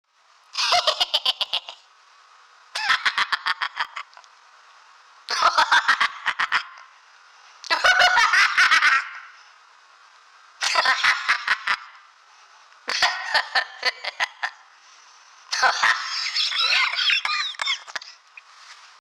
Cartoon Laugh
Cartoon chortle chuckle clown demon devil evil giggle sound effect free sound royalty free Funny